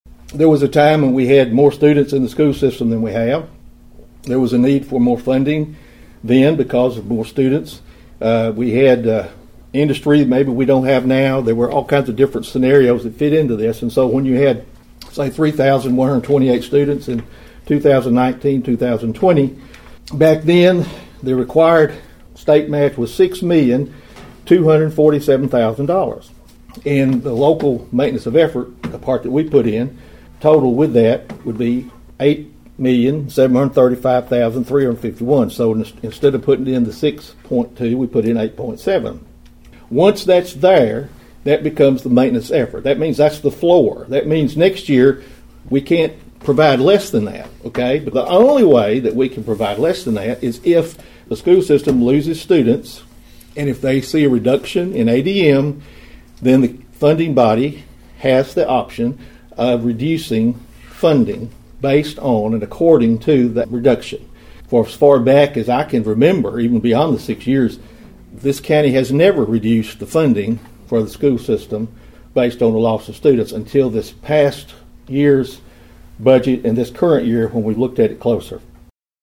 During his nearly 17-minute address to the Budget Committee last week, Mayor Carr said County Commissioners have exceeded their maintenance of effort for the schools.(AUDIO)